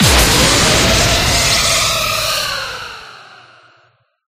Collapse4.ogg